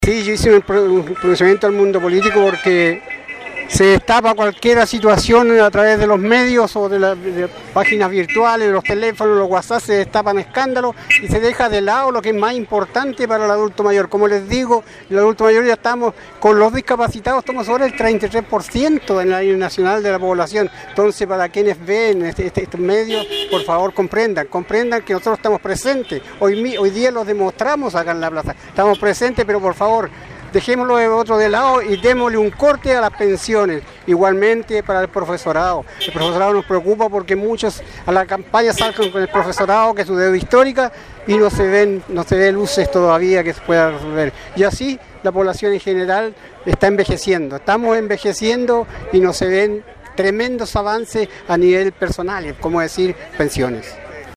discurso en Plaza de Armas